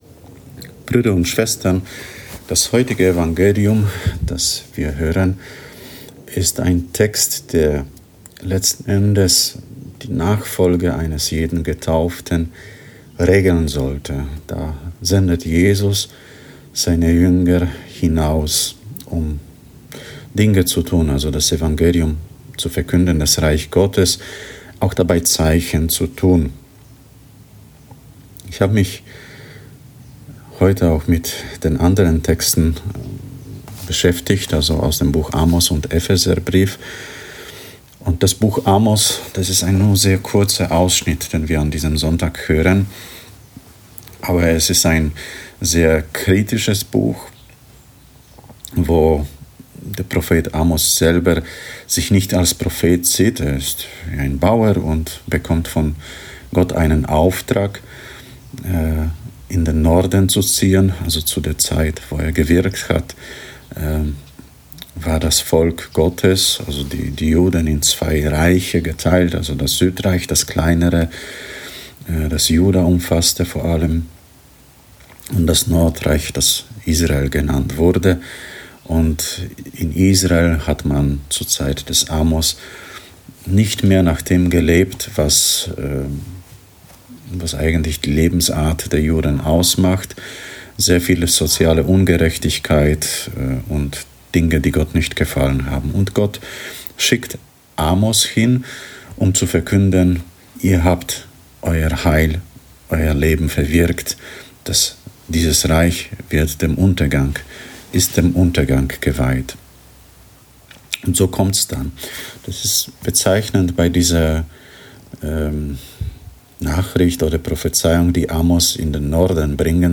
Eine Predigt zum 15. Sonntag im Jahreskreis B von